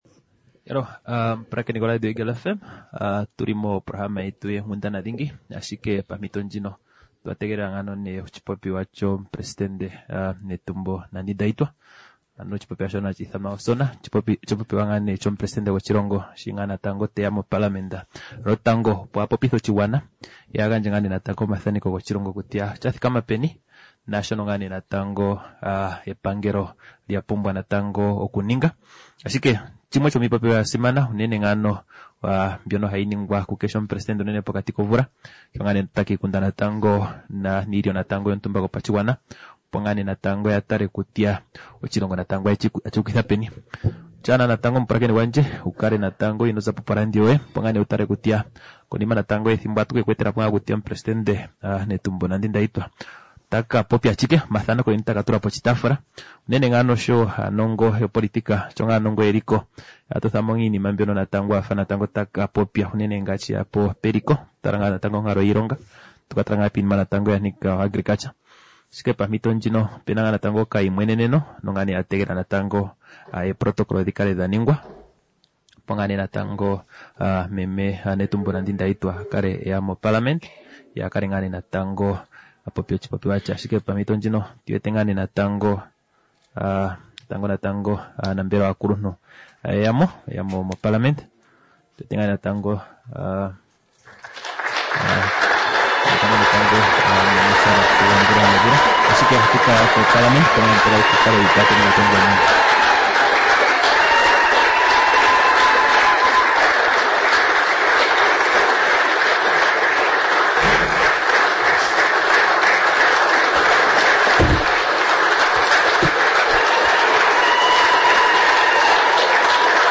STATE OF NATION ADDRESS (24 APRIL 2025)